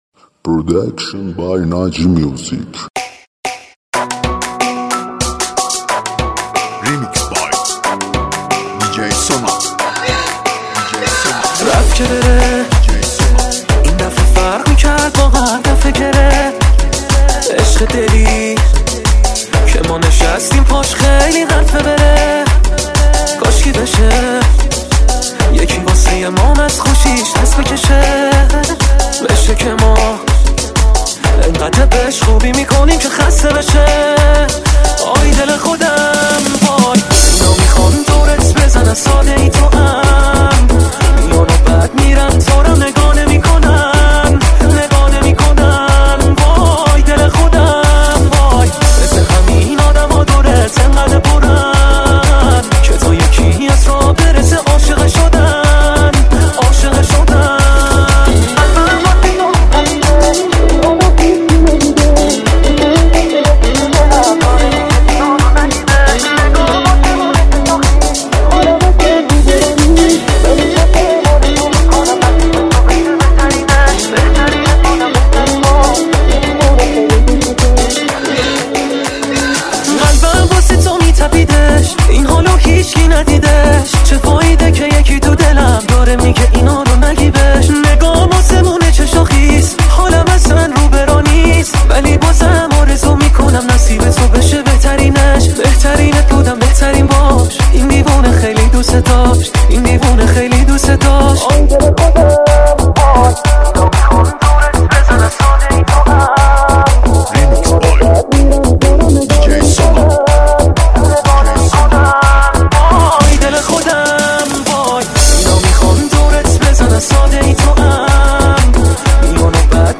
آهنگ شاد تریبال مخصوص پارتی و رقص
ریمیکس های شاد تریبال